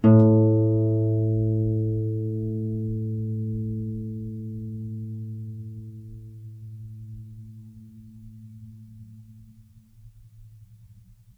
KSHarp_A2_mf.wav